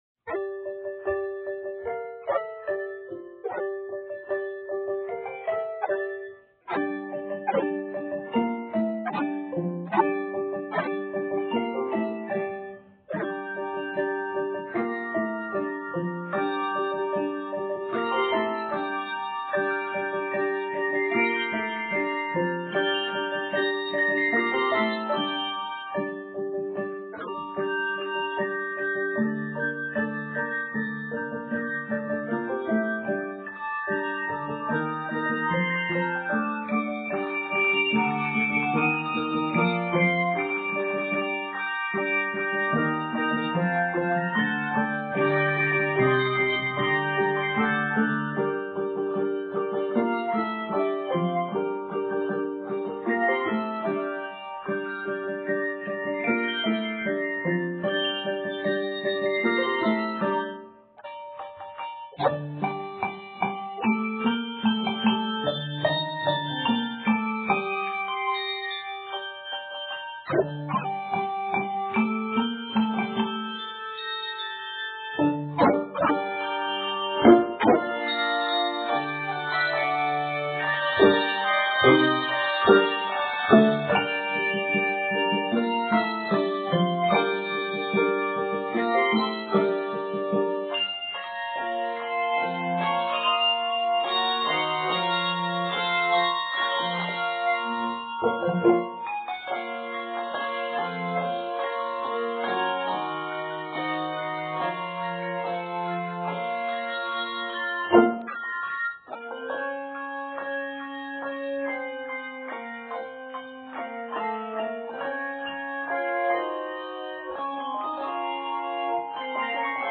is a driving, confident arrangement of three hymn tunes